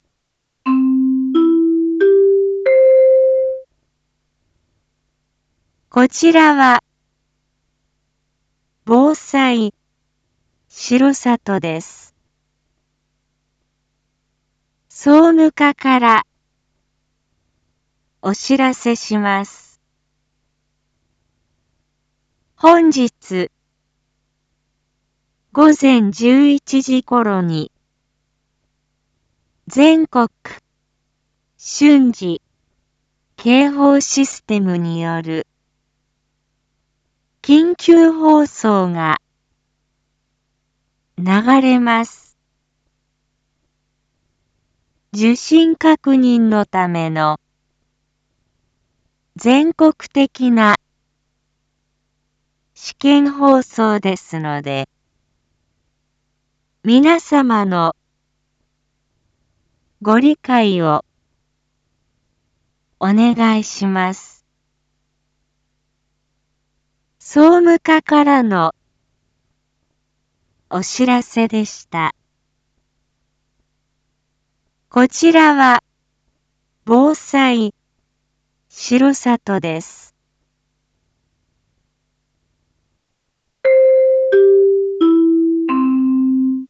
一般放送情報
Back Home 一般放送情報 音声放送 再生 一般放送情報 登録日時：2024-05-22 07:01:33 タイトル：全国瞬時警報システム(Jアラート)訓練のお知らせ インフォメーション：こちらは防災しろさとです。